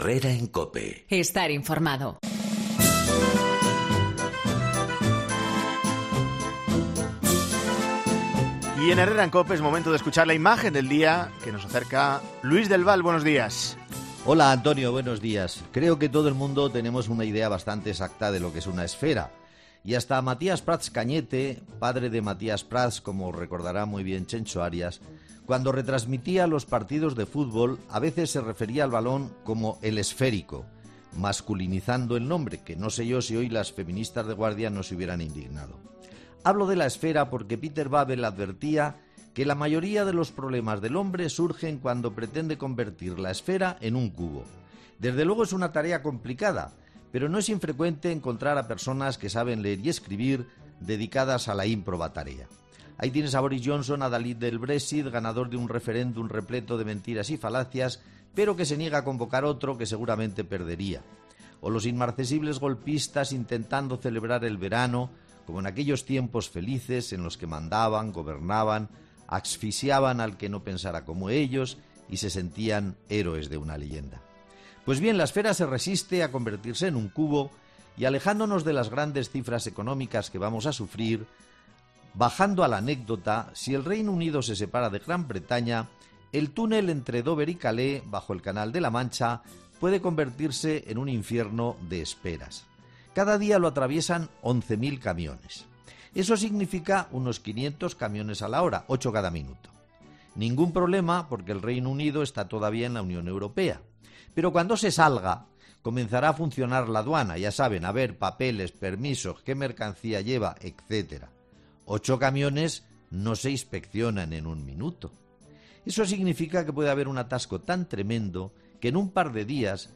El periodista analiza en 'Herrera en COPE' el secesionismo europeo y catalán